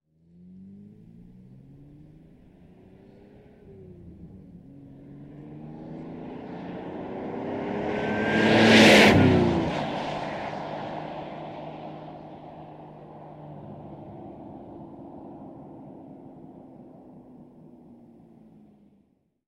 Звуки гоночных машин
Звук автомобиля на высокой скорости проносится мимо микрофона